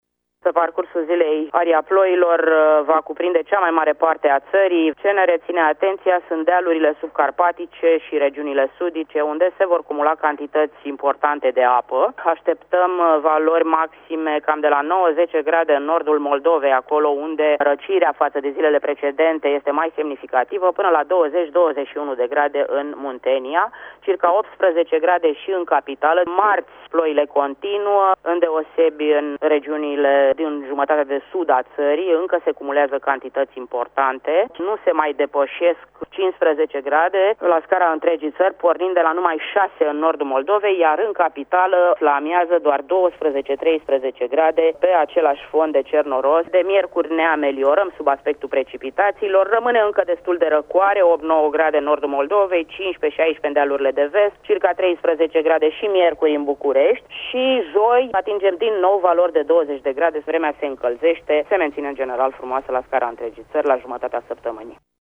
Cu detalii, meteorologul